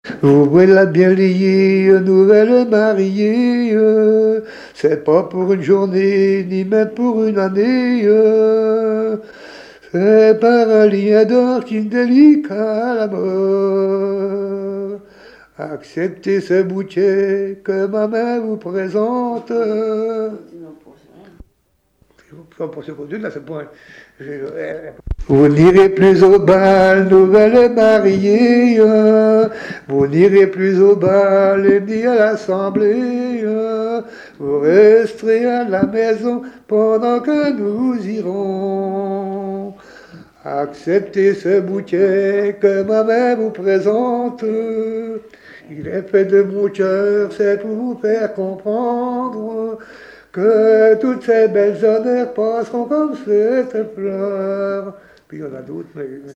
Genre strophique
Chansons traditionnelles et témoignages
Pièce musicale inédite